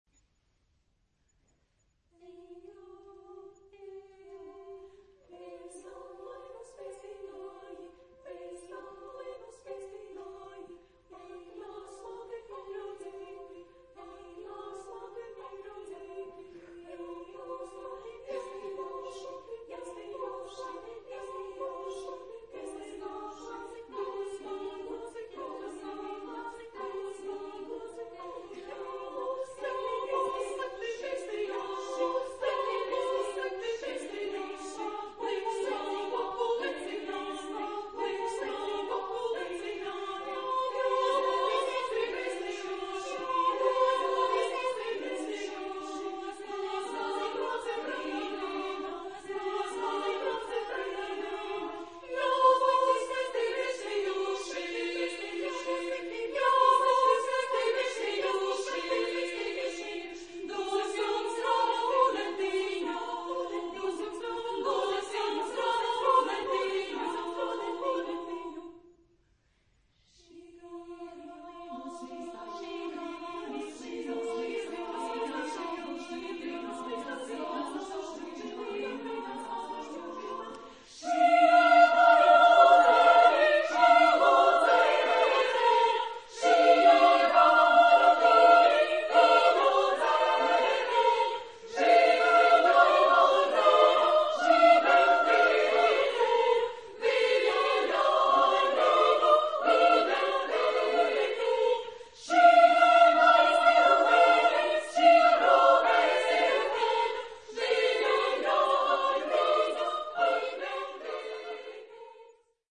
Genre-Style-Form: Secular ; Contemporary
Mood of the piece: joyous
Type of Choir: SSSAAA  (6 women voices )
Tonality: F minor